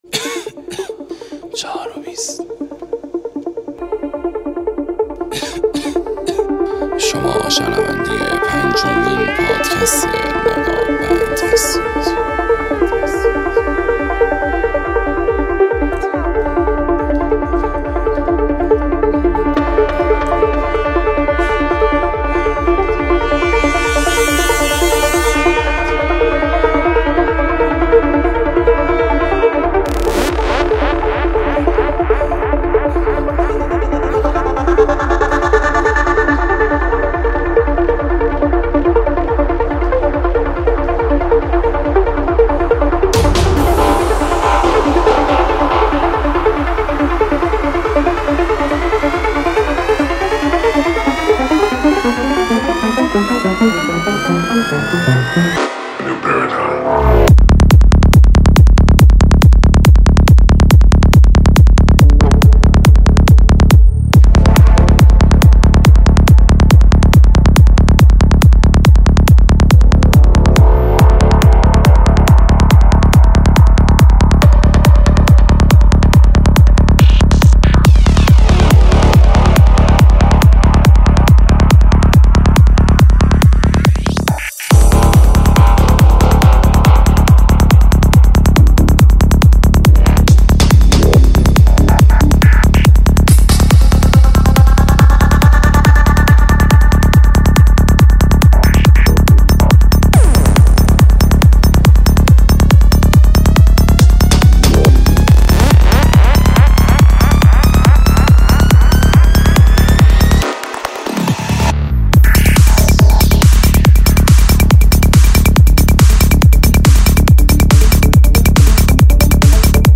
دانلود ریمیکس طولانی بیس دار ماشین و سیستم